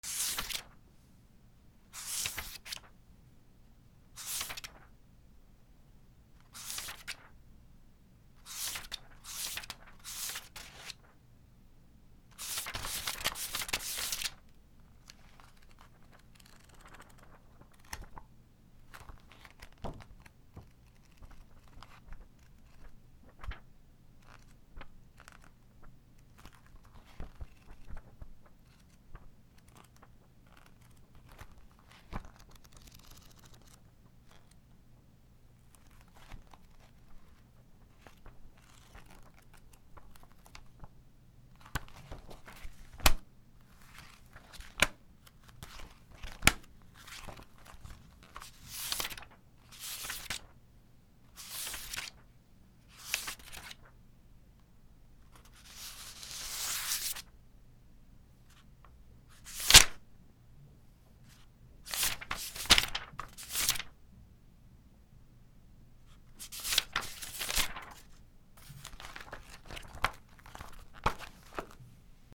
/ M｜他分類 / L01 ｜小道具 / 文房具・工作道具
ページをめくる 本を置くなど 語学テキスト